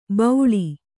♪ bauḷi